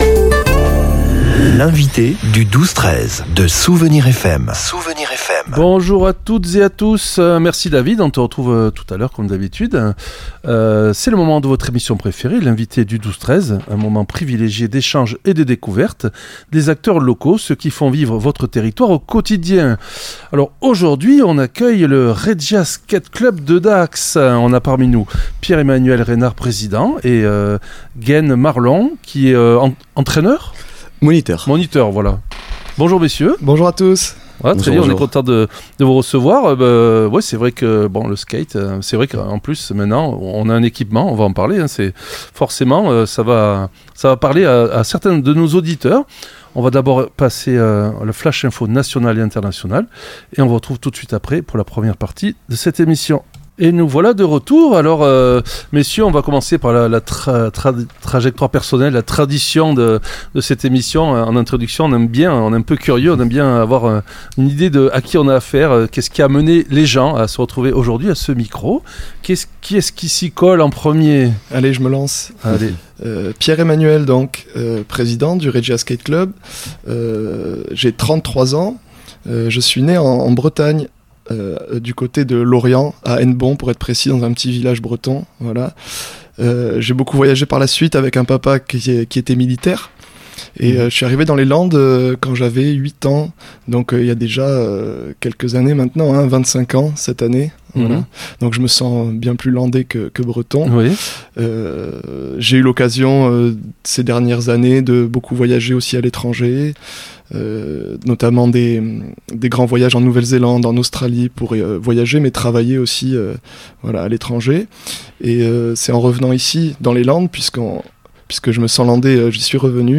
Pendant une heure d’échange, nos invités ont présenté ce club dynamique qui fait du skateboard un outil de sport, de transmission et de vivre-ensemble.